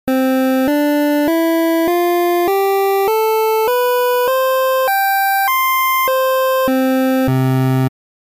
非対称矩形波（Pulse）−鋸波とはまた違う感じで、面白い倍音を含む波形。